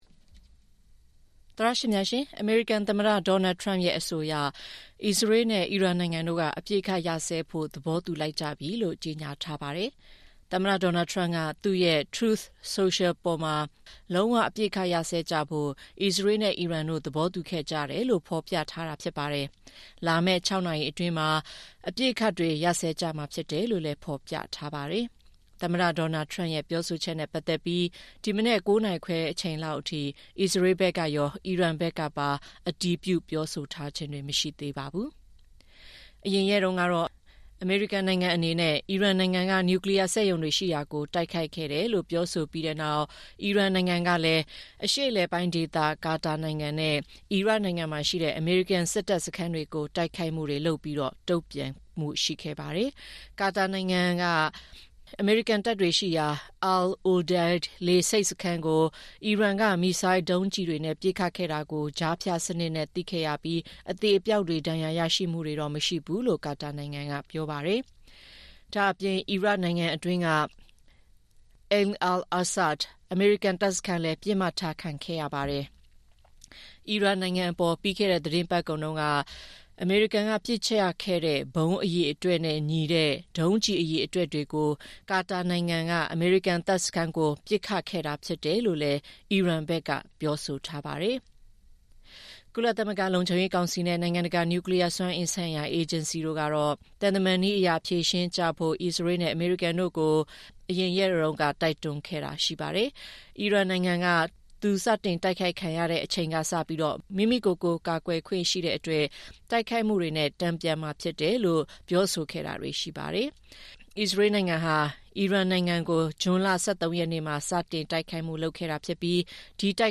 အစ္စရေးမှာ အလုပ်လုပ်သူ မြန်မာတစ်ဦးရဲ့ ပြောပြချက်